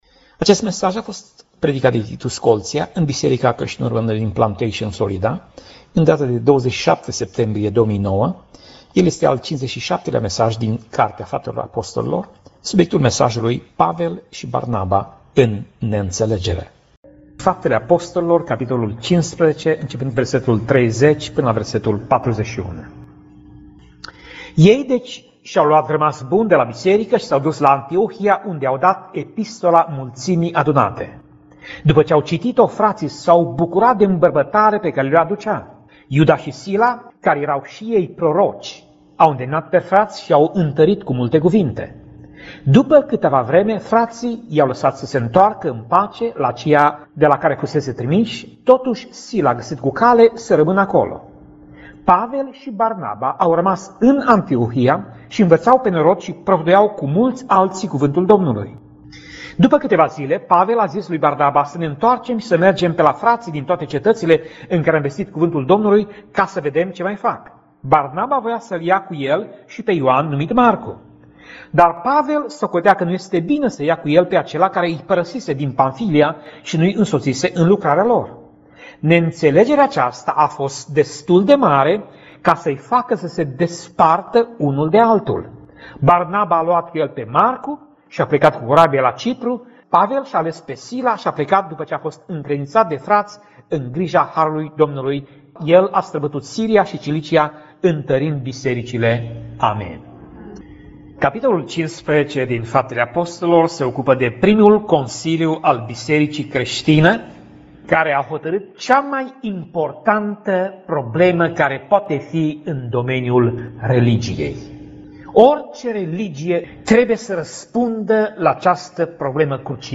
Pasaj Biblie: Faptele Apostolilor 15:33 - Faptele Apostolilor 15:41 Tip Mesaj: Predica